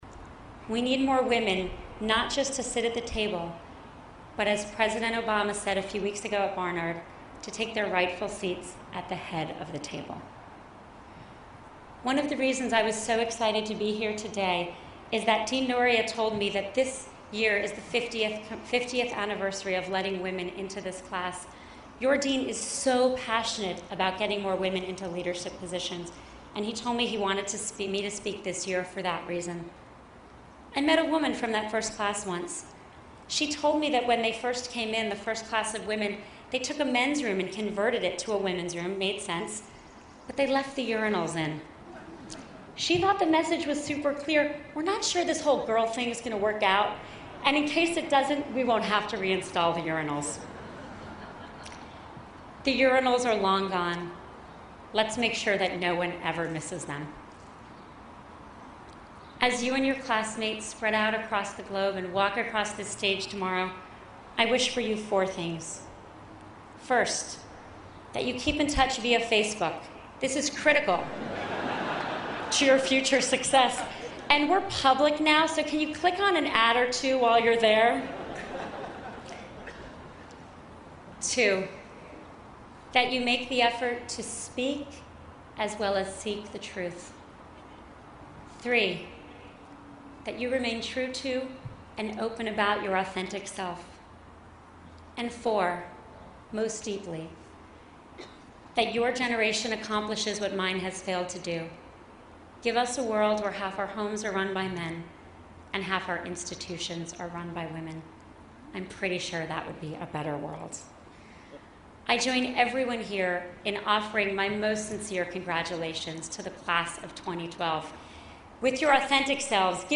公众人物毕业演讲第188期:桑德伯格2012哈佛商学院(13) 听力文件下载—在线英语听力室